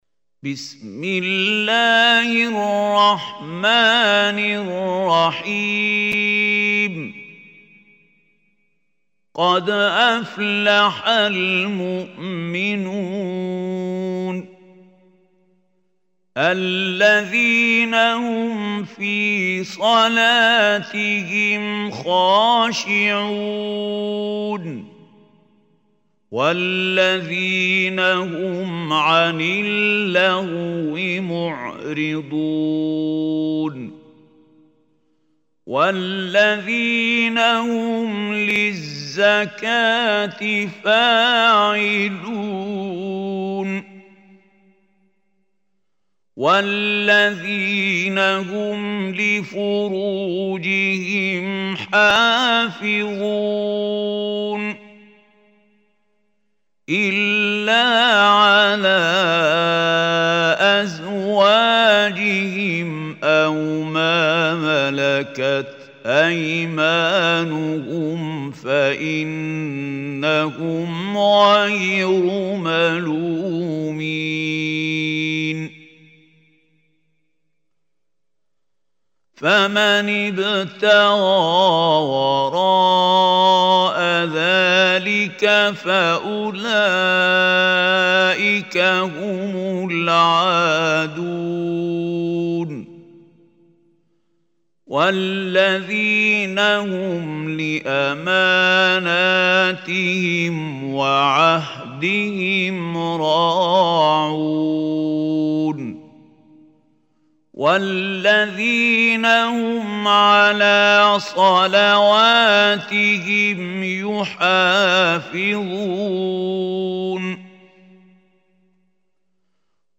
Listen online and download beautiful tilawat / recitation of Surat Al Muminoon. Listen recitation in the voice of Mahmoud Khalil Al Hussary.